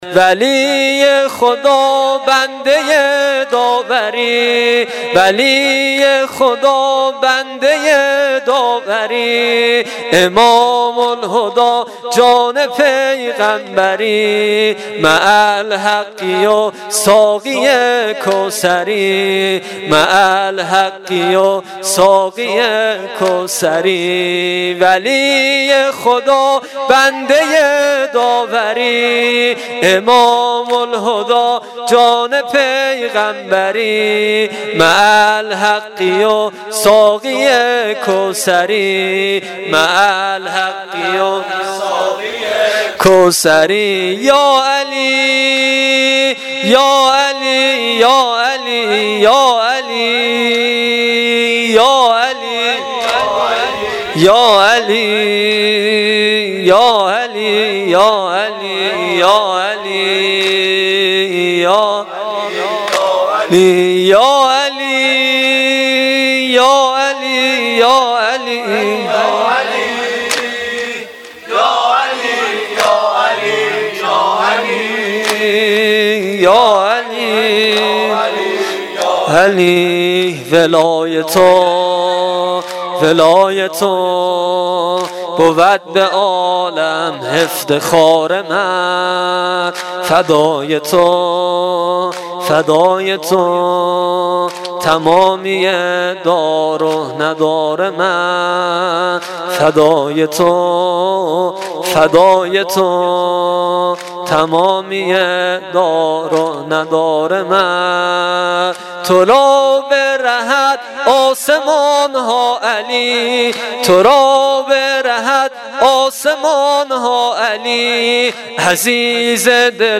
واحد تند شب 21 ماه رمضان